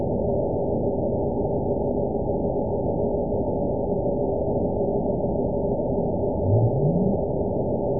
event 922720 date 03/22/25 time 14:47:13 GMT (2 months, 3 weeks ago) score 9.72 location TSS-AB04 detected by nrw target species NRW annotations +NRW Spectrogram: Frequency (kHz) vs. Time (s) audio not available .wav